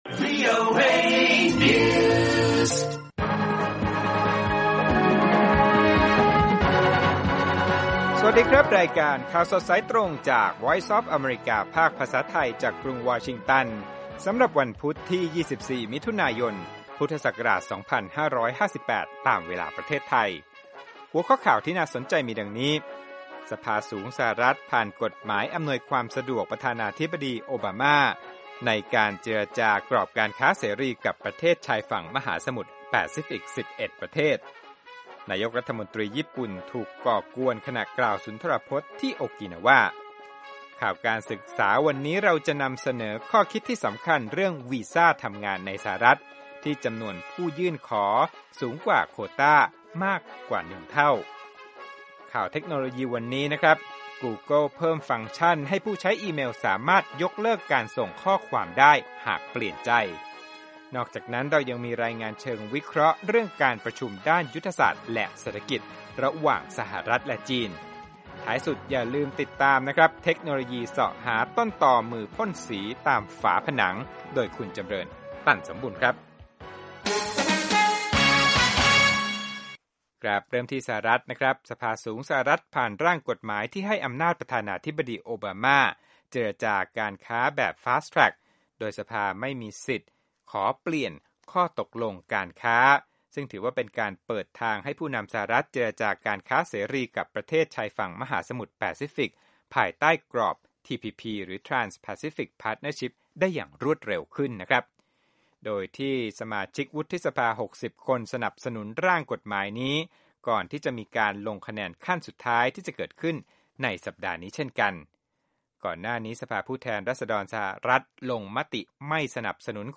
ข่าวสดสายตรงจากวีโอเอ ภาคภาษาไทย 8:30–9:00 น.วันพุธที่ 24 มิถุนายน พ.ศ. 2558